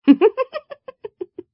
Giggle.wav